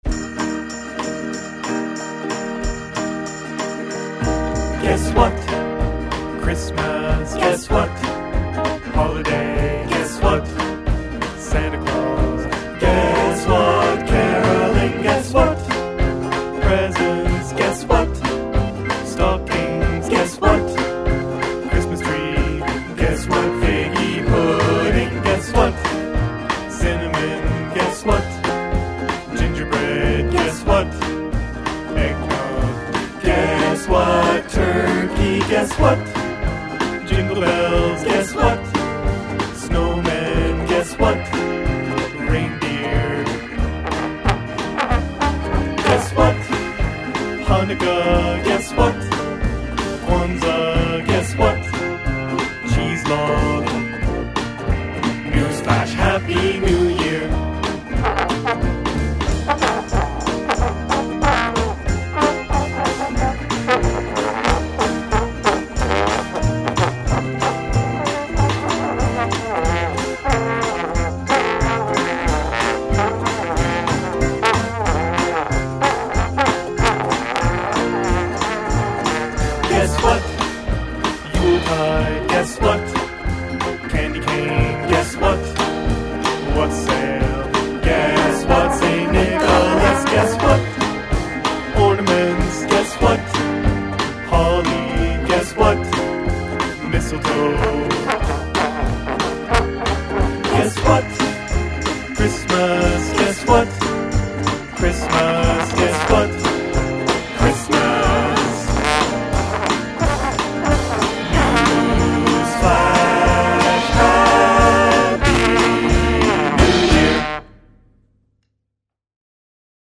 voice, trombone
voice, bass
Recorded in Toronto ON Canada @ THE FARM, Dec 10 to 18, 2007